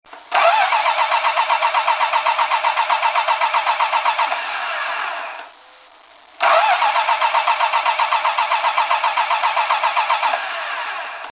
The Australian starter is smaller and lighter than the large-frame Chrysler item, but cranks the engine an estimated 25% faster. It, too, doesn't sound quite like any conventional Chrysler starter.
crank and start my Dodge.